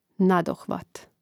nȁdohvāt nadohvat